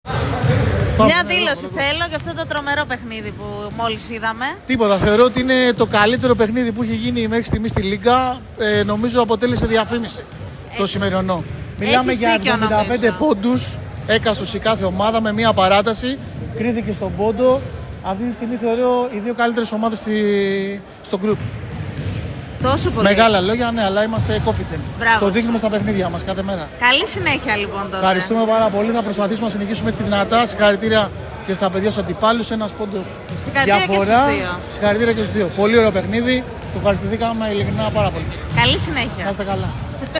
GAME INTERVIEWS
Παίκτης Neptune Group